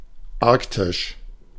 Ääntäminen
Synonyymit boréal Ääntäminen France: IPA: [aʁk.tik] Haettu sana löytyi näillä lähdekielillä: ranska Käännös Ääninäyte Adjektiivit 1. nördlich 2. arktisch 3. nordpolar 4.